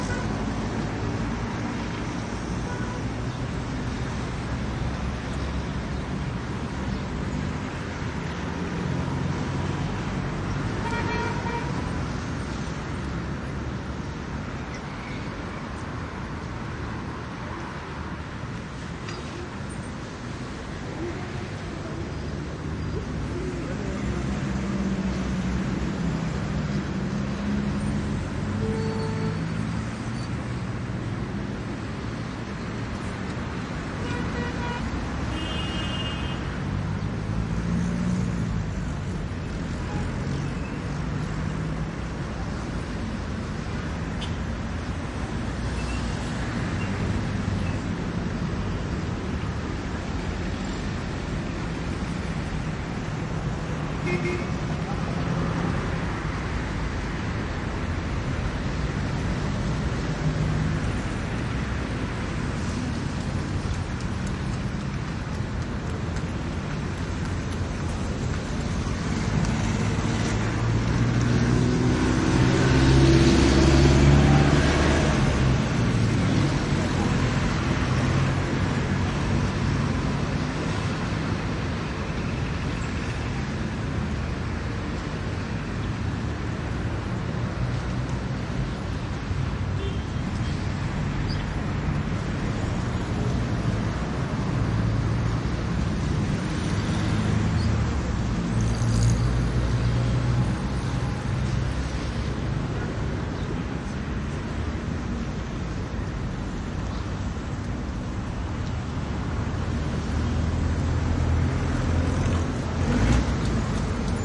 加沙 " 交通媒介 中东 繁忙的林荫道上的喇叭声1 喉音汽车缓慢地移动 加沙地带 2016年
描述：交通中等繁忙的中东繁忙的林荫大道喇叭1嘶哑的汽车缓慢移动加沙地带2016.wav
标签： 交通 媒体 中东 喇叭 honks C知音街 林荫大道 车水马龙
声道立体声